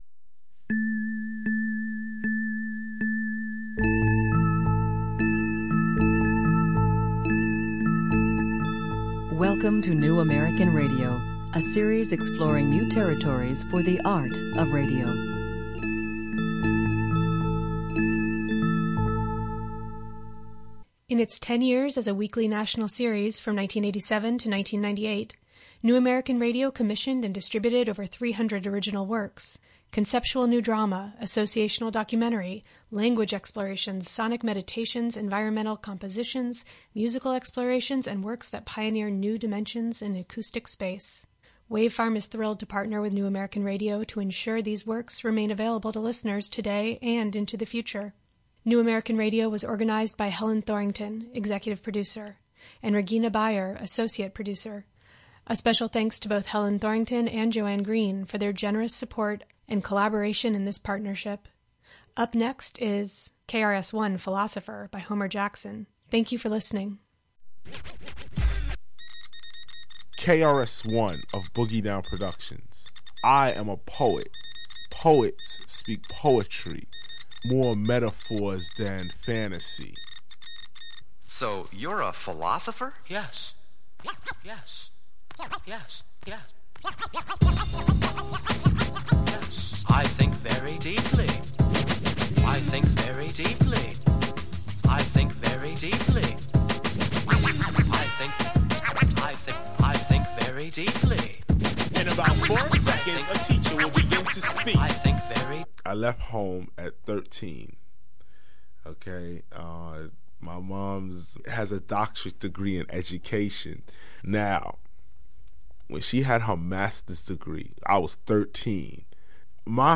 A hip-hop style music documentary about Kris Parke...